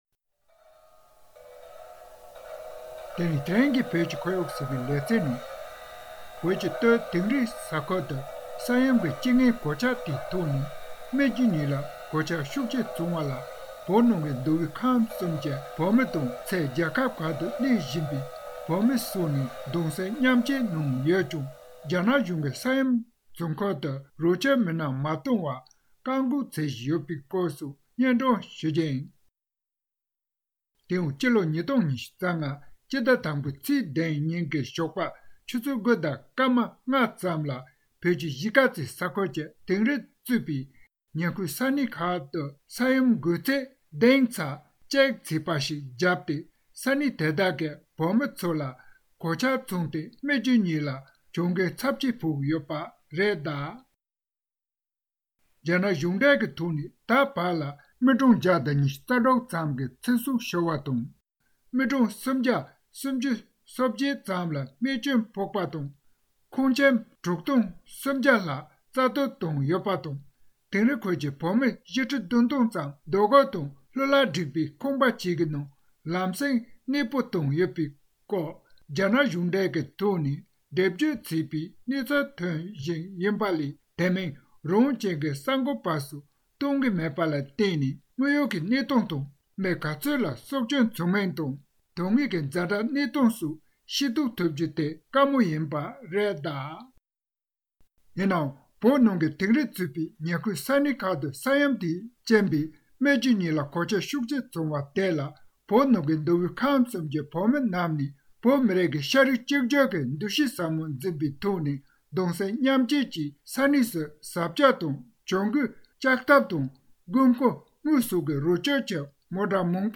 གནས་ཚུལ་སྙན་སྒྲོན་ཞུས་པ་གསན་རོགས།།